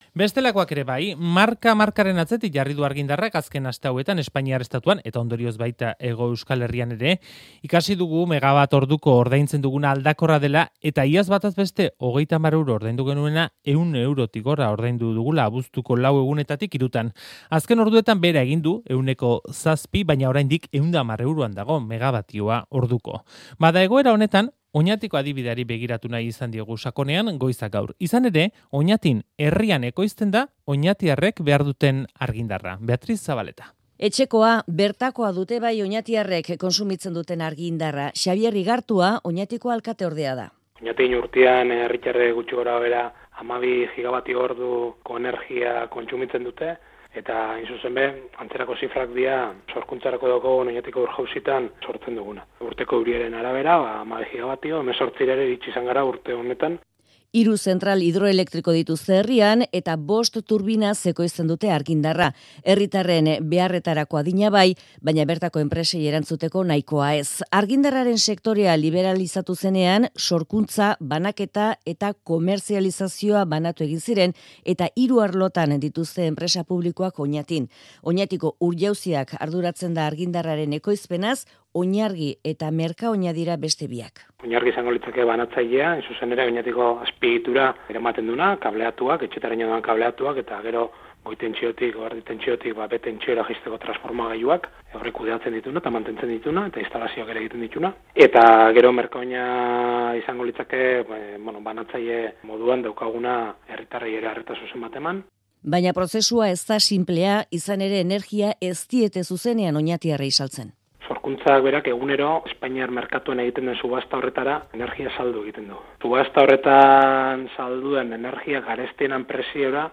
Hiru zentral hidroelektriko dituzte herrian eta bost turbinaz ekoizten dute argindarra. Xabier Igartua Oñatiko alkateordeak eman ditu xehetasunak.